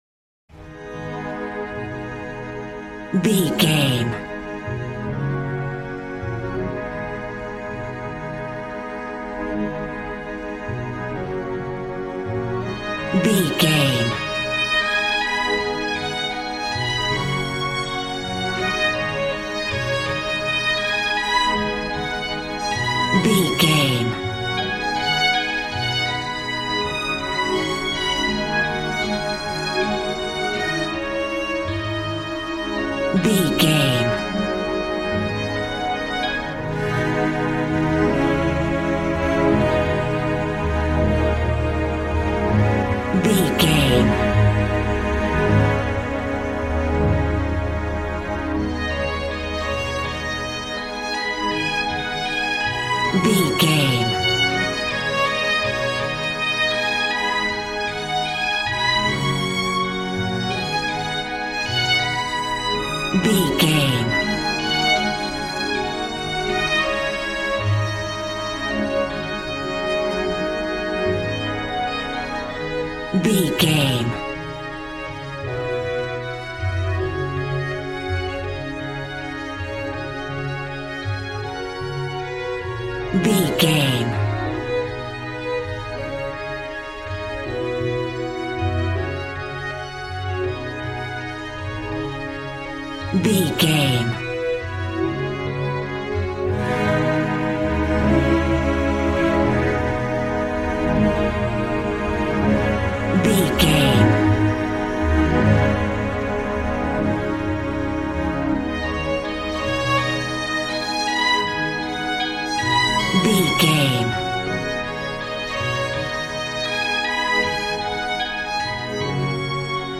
Aeolian/Minor
A♭
Fast
joyful
conga